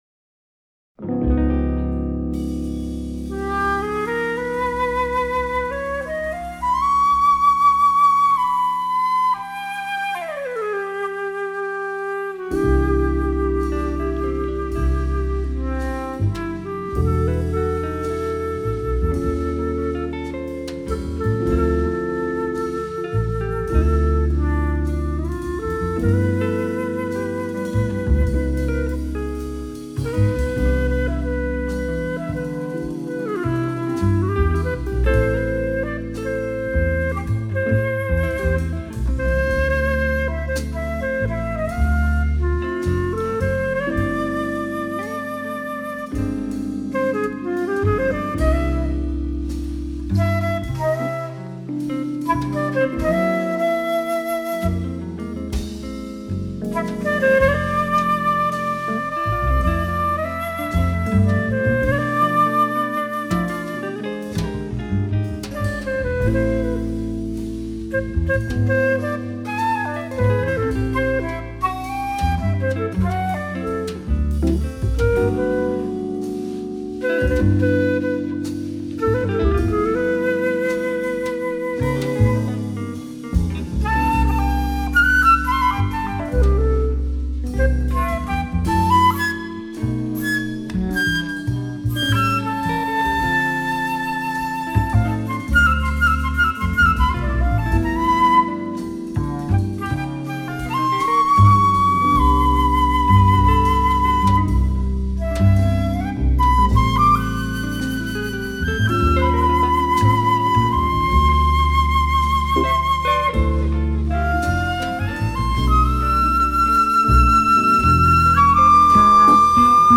The Best In British Jazz
Recorded at Wave Studios, 25th / 26th September 1983